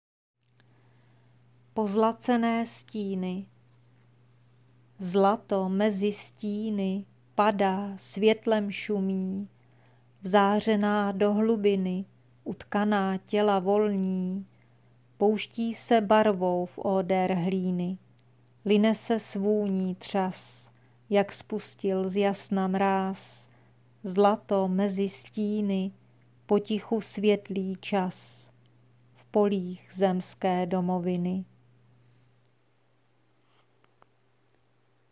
Miniatura, hříčka » Příroda